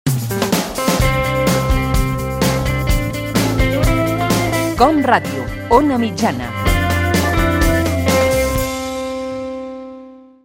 Indicatiu de la ràdio a l'ona mitjana.